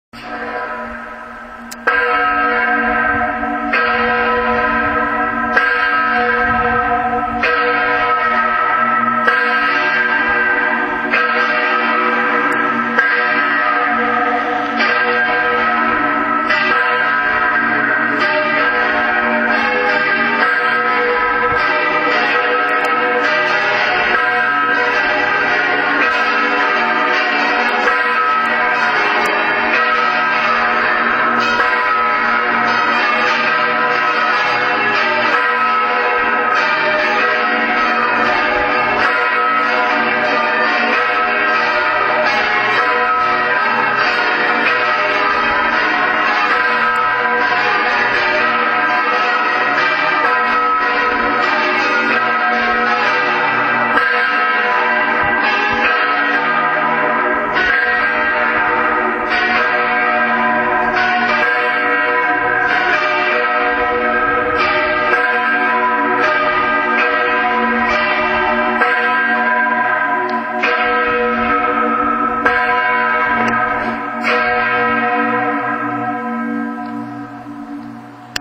Die Namen der Glocken der Marlinger Pfarrkirche sind: Große (Anna Maria), Zwölferin, Zehnerin, Siebnerin, Onderte, Kleine und Zügenglocke. Mitte der 1950iger-Jahre wurde des Geläute elektrifiziert.
Die Glocken der Marlinger Pfarrkirche: